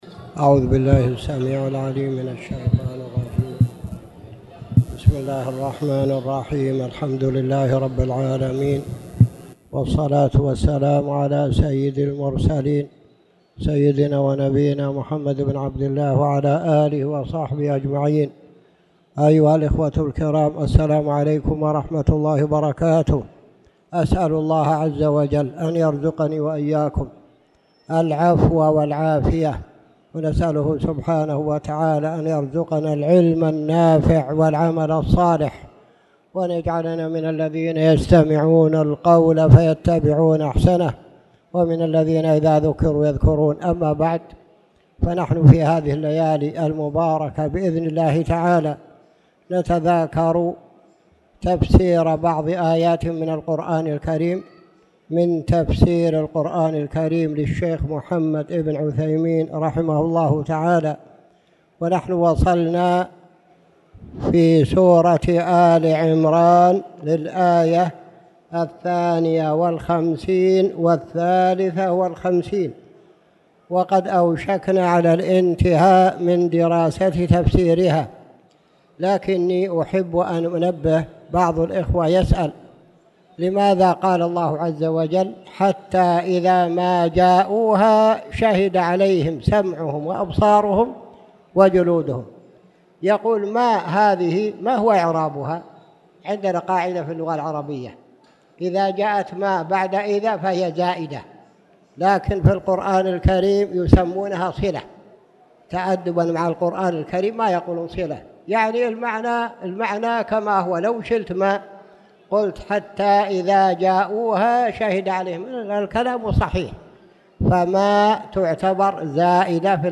تاريخ النشر ٢٩ جمادى الأولى ١٤٣٨ هـ المكان: المسجد الحرام الشيخ